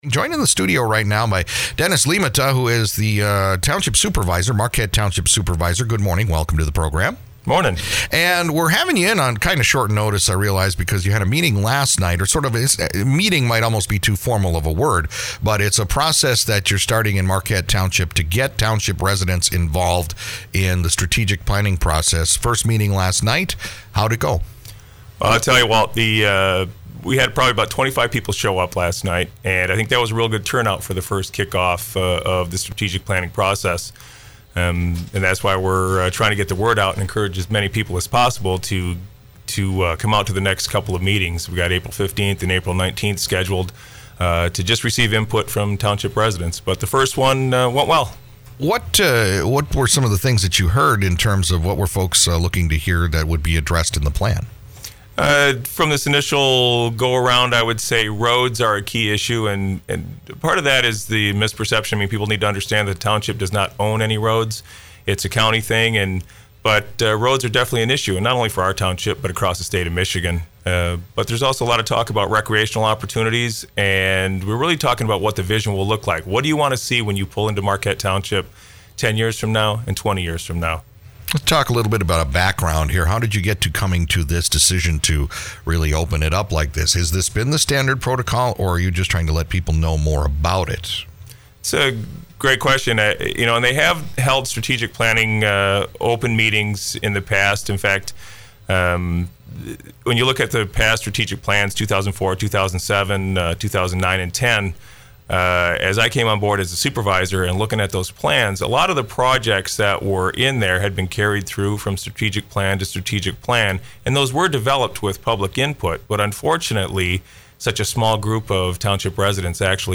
Marquette Township Supervisor Dennis Liimatta came by the studios this morning to discuss and recap the first public input session that was hosted by the Marquette Township Board of Trustees designed to gather and organize comments and ideas from township residents in an effort to maintain transparency in the strategic planning process for the future of the township.